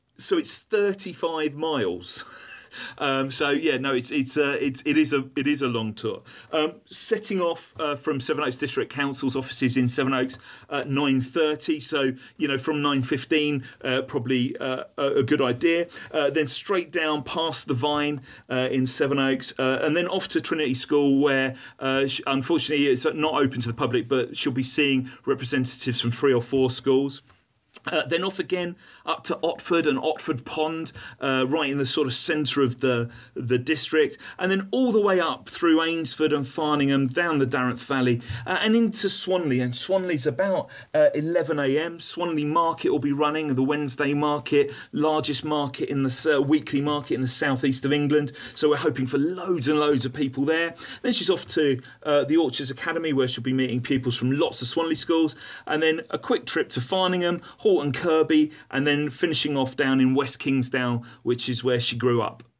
Listen: Sevenoaks District Council leader Cllr Peter Fleming explains the Lizzy Yarnold open-top bus tour route - 17/04/18